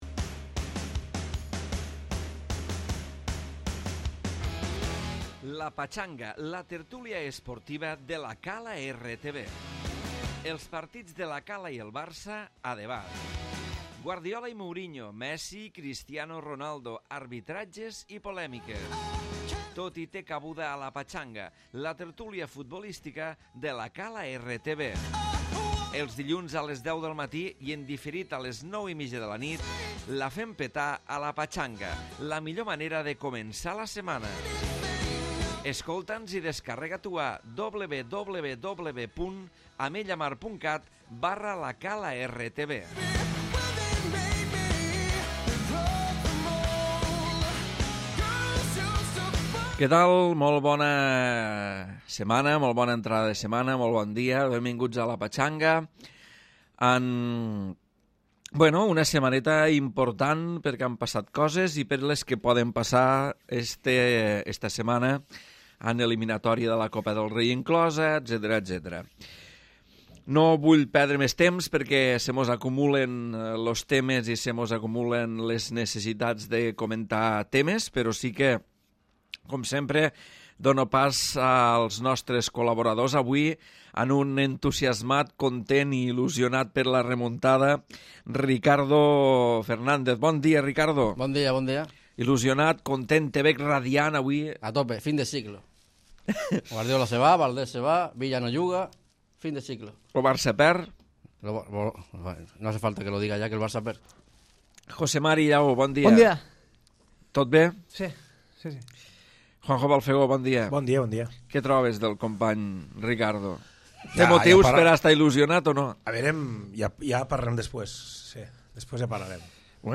Tertúlia d'actualitat futbolística amb marcat accent blaugrana